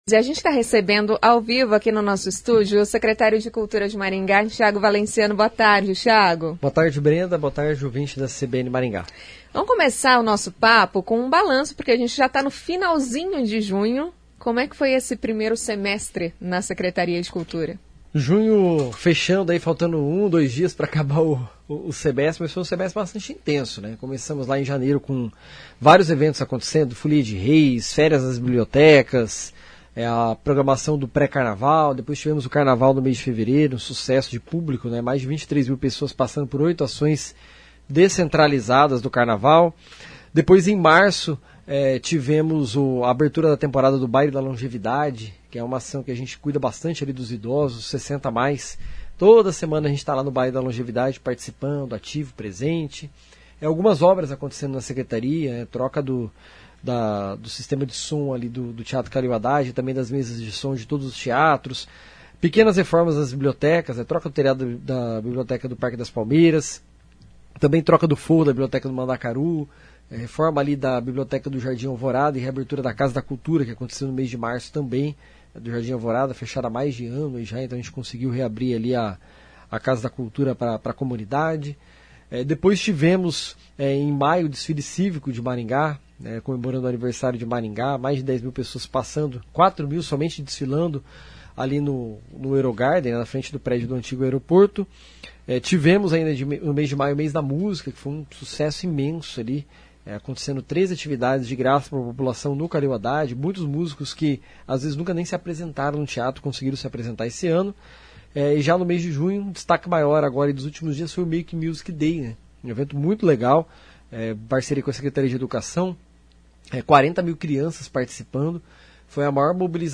Em entrevista à CBN Maringá, o Secretário de Cultura, Tiago Valenciano, fez um balanço sobre as ações já realizadas